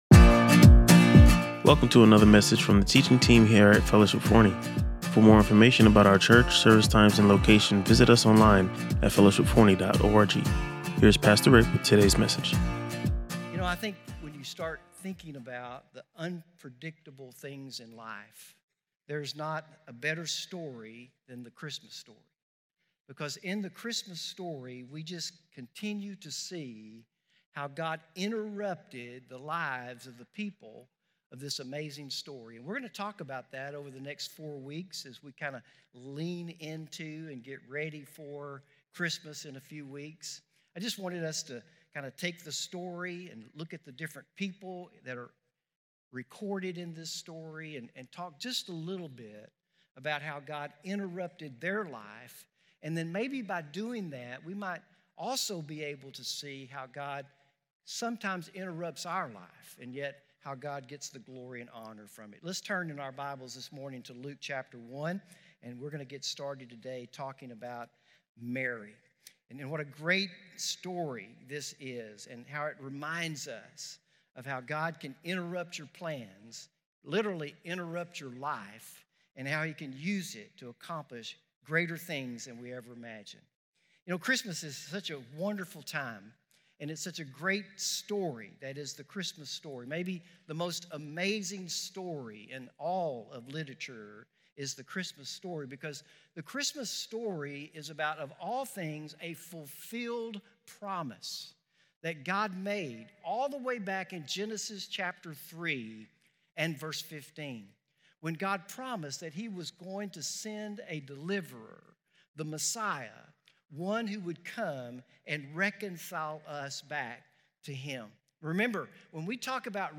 He emphasized how Mary, a young woman with ordinary plans, encountered an extraordinary calling that completely redirected her path. The sermon highlighted that just as Mary had to face difficult conversations and community scrutiny, God often calls us to step out in faith despite challenges.